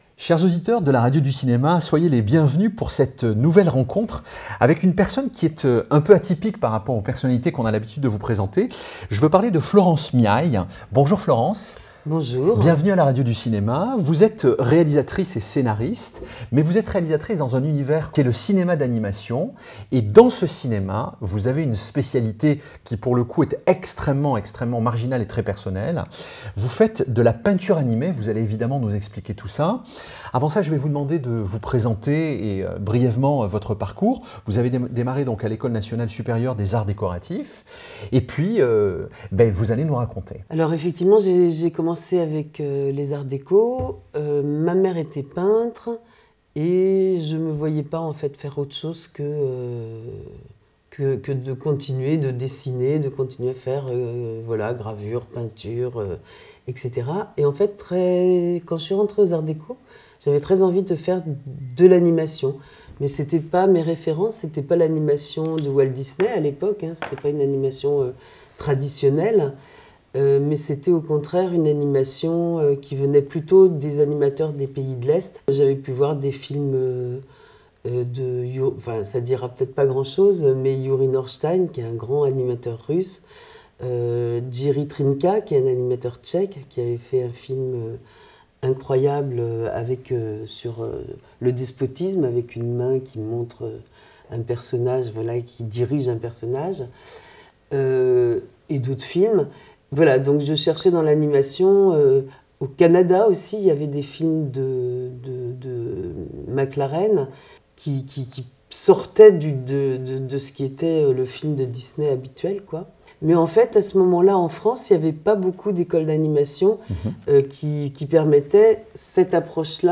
interviewée